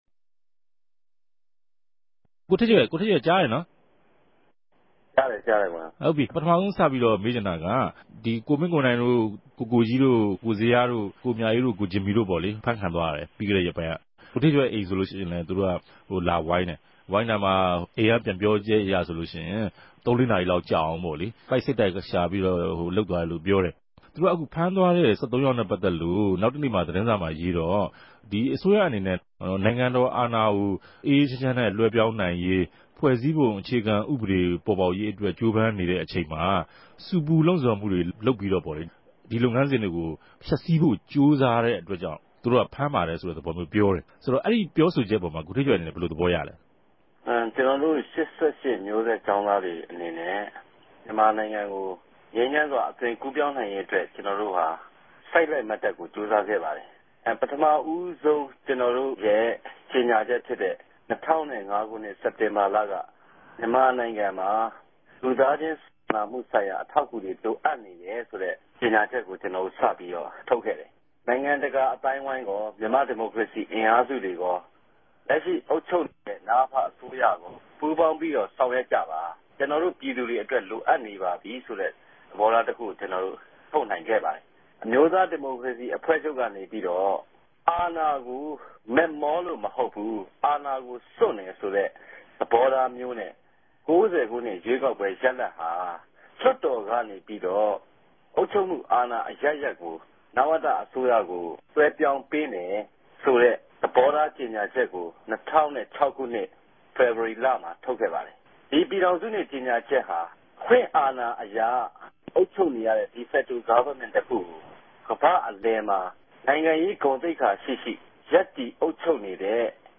ကေဵာင်းသားခေၝင်းဆောင် ကိုဌေး့ကယ်ကို RFAမြ ဆက်သြယ်မေးူမန်း